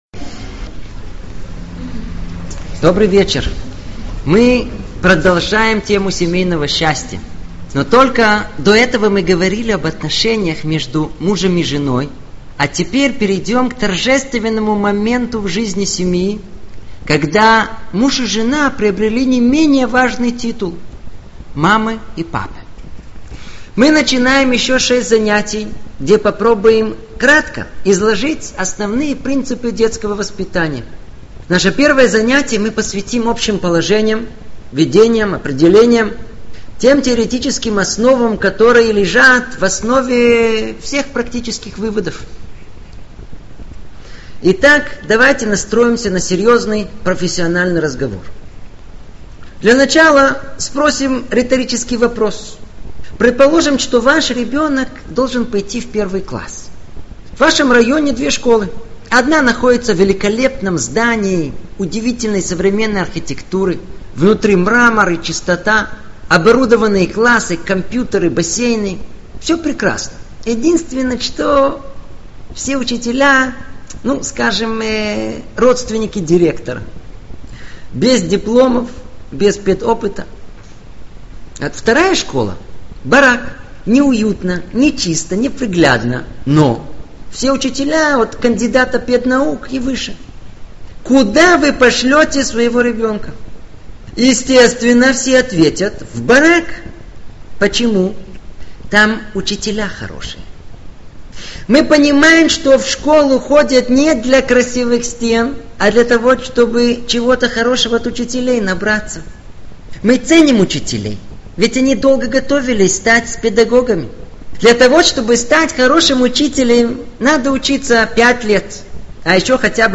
Популярный цикл аудиоуроков об основных принципах воспитания детей, диктуемых иудаизмом и подтвержденных современными исследованиями в области психологии. 2004 год.